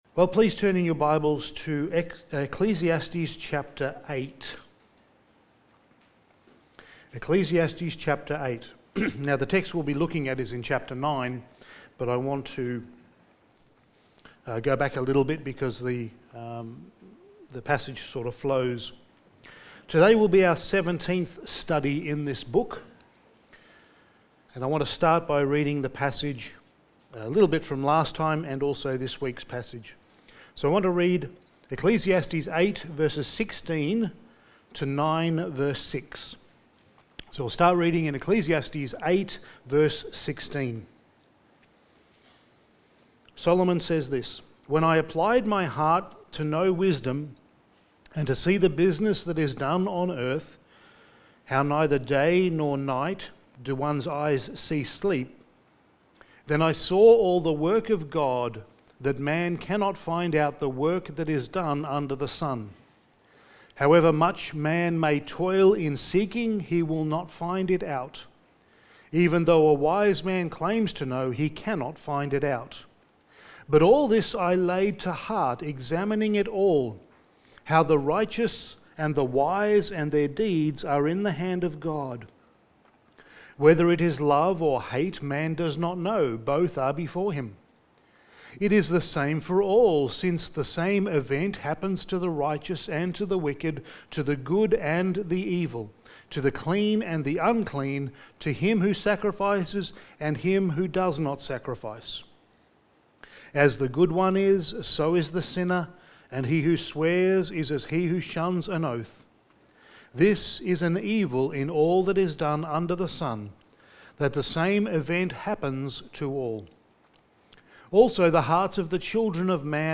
Passage: Ecclesiastes 9:1-6 Service Type: Sunday Morning